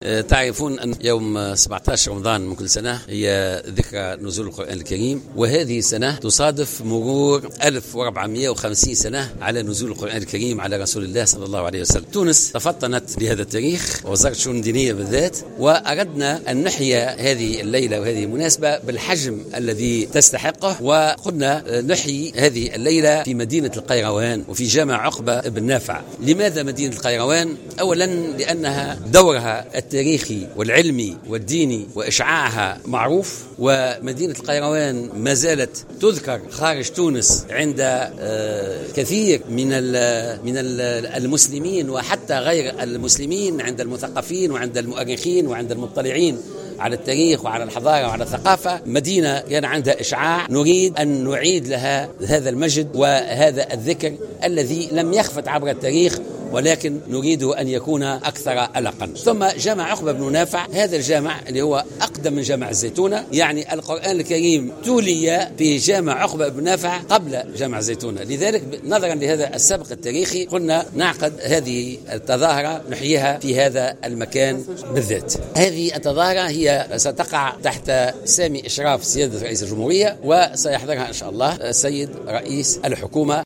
قال وزير الشؤون الدينية محمد خليل اليوم السبت إنه سيتم إحياء ذكرى ليلة نزول القرآن الكريم في جامع عقبة بن نافع بالقيروان.